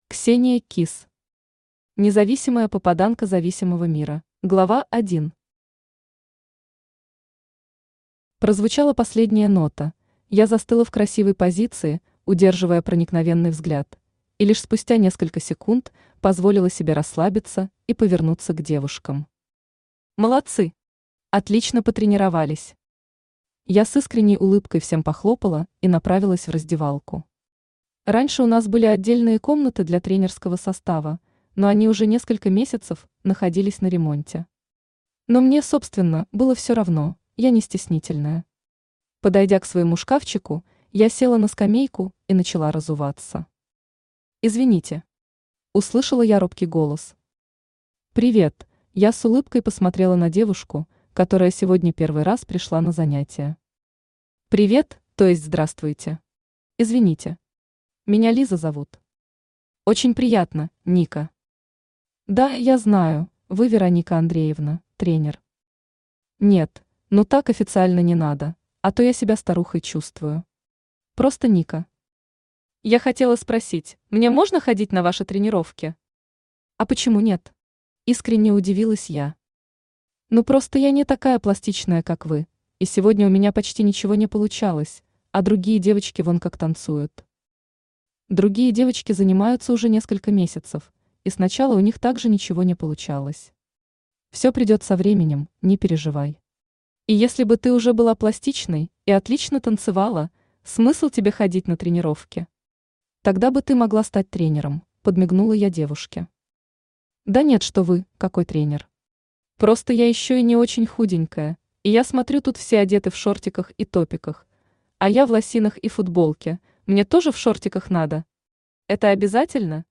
Аудиокнига Независимая попаданка зависимого Мира | Библиотека аудиокниг